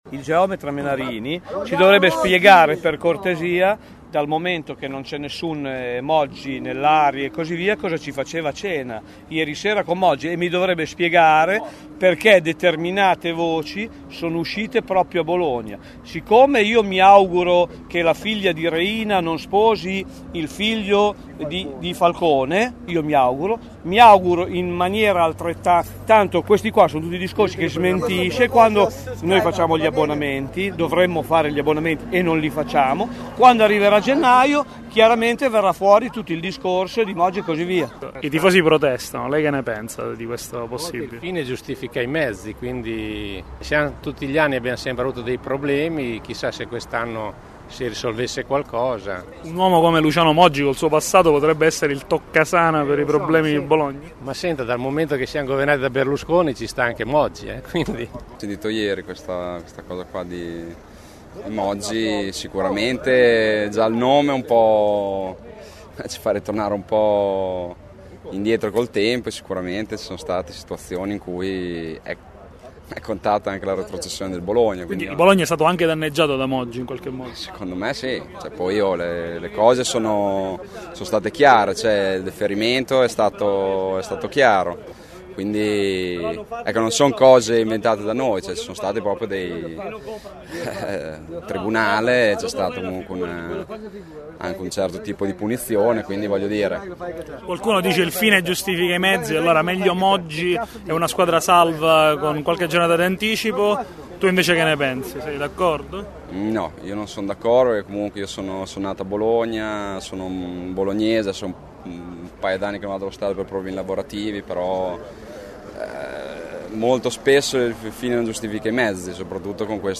Ieri erano una trentina davanti al centro sportivo di Casteldebole.
Ascolta i commenti
i-tifosi.mp3